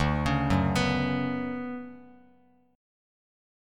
DM13 chord